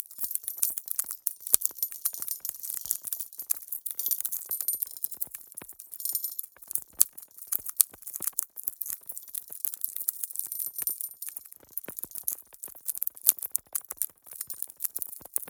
Free Frost Mage - SFX
Crystallize_loop_08.wav